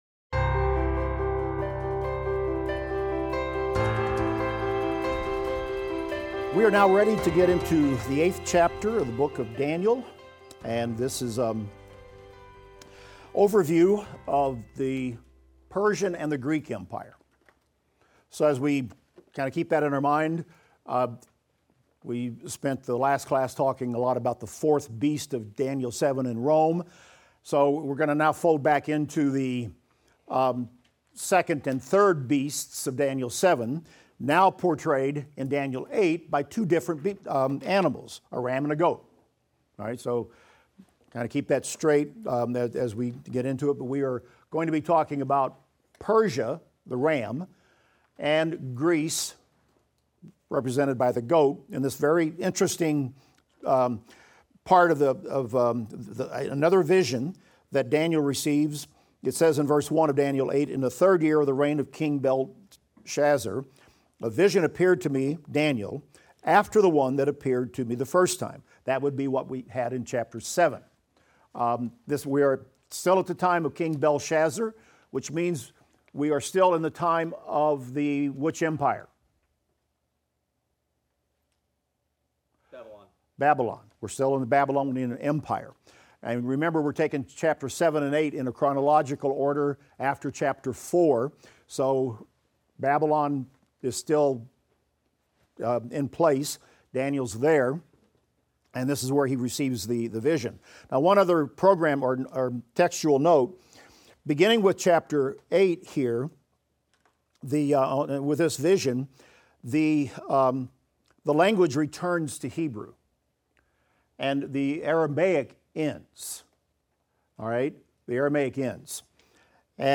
Daniel - Lecture 12 - audio.mp3